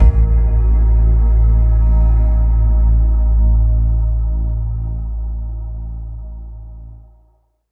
Death-Sound.mp3